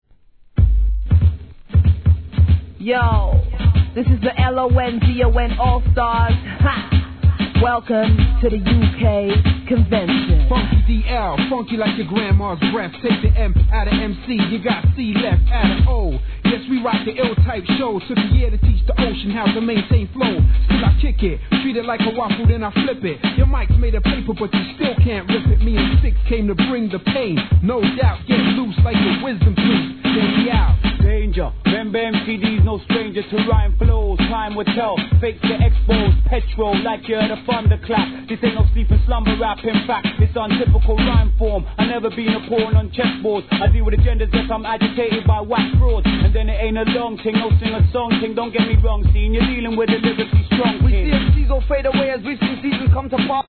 1. HIP HOP/R&B
オールドスクール調のドラムブレイクに、個性派 揃いのロンドン発のMCリレー!